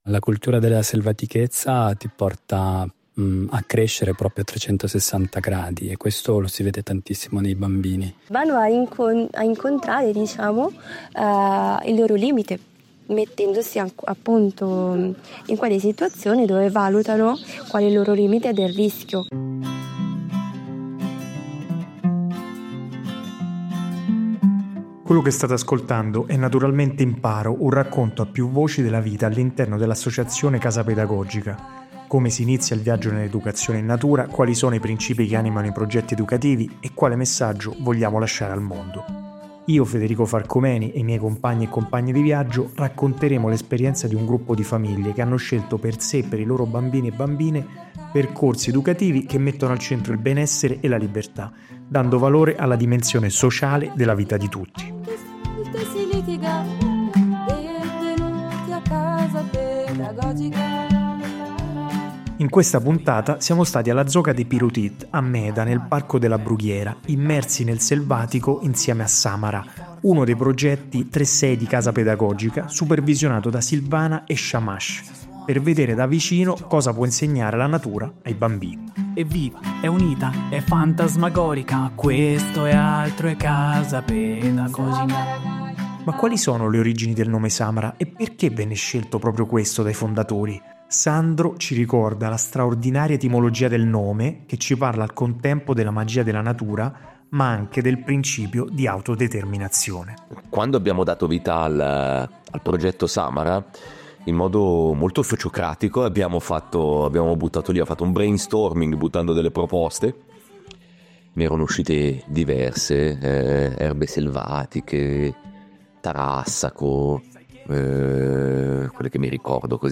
In questa puntata siamo stati alla Zoca dei Pirutit, a Meda, nel Parco della Brughiera, immersi nel selvatico insieme a Samara